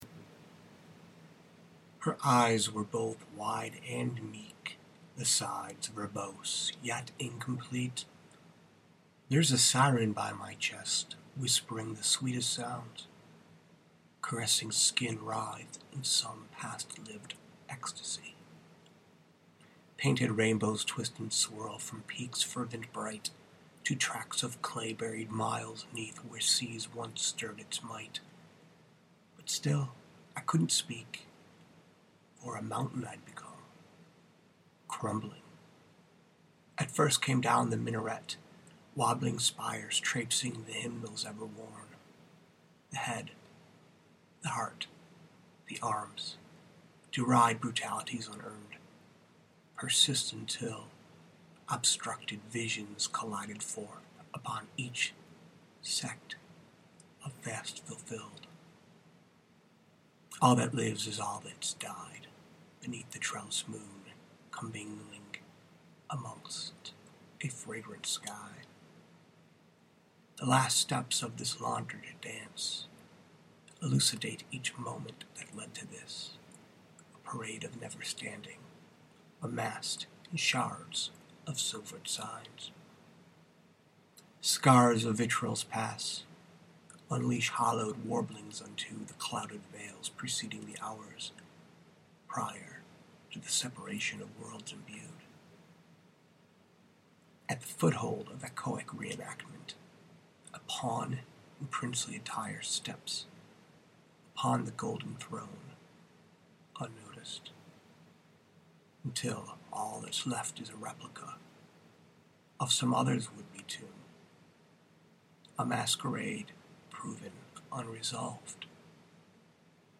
poetry, poem